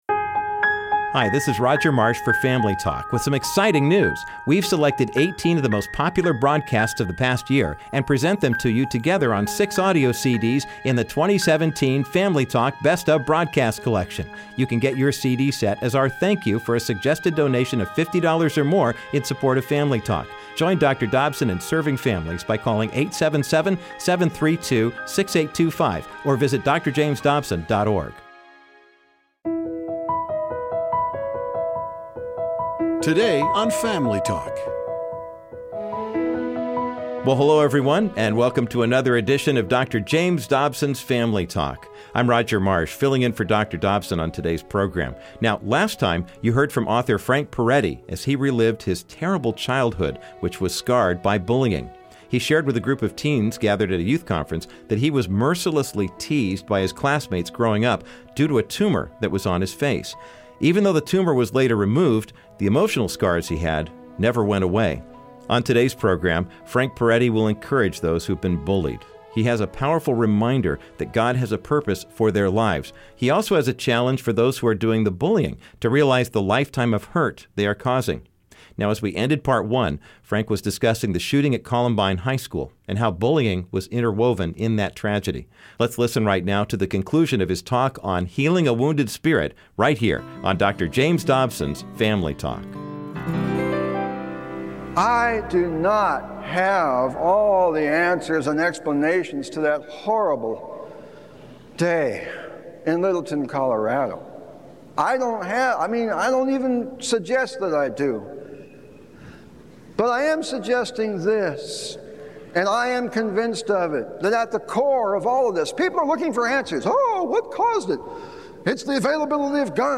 you will hear part two of author Frank Perettis testimony, which made our Best of the Year list.Frank will reassure those who have been bullied that God loves and cares for them and challenged those who are bullies to recognize the profound hurt their actions cause. Listen to the conclusion to this powerful speech, today on Dr. James Dobsons Family Talk.